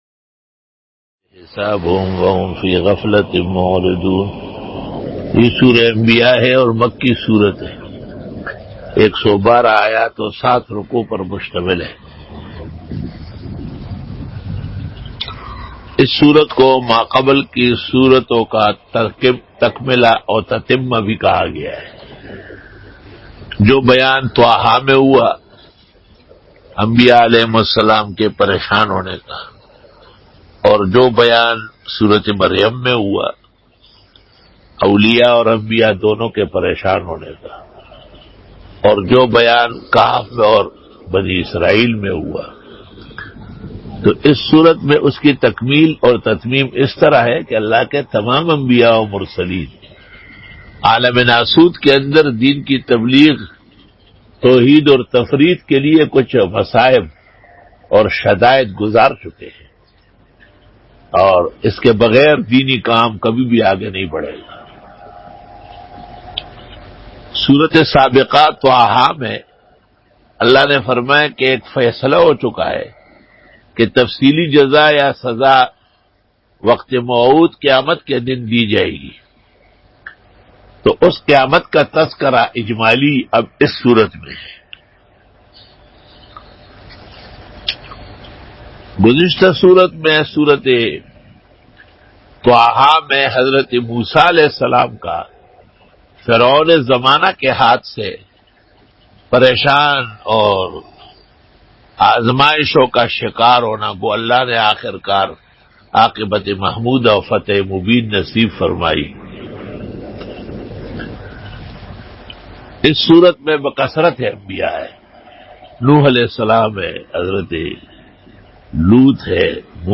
Bayan by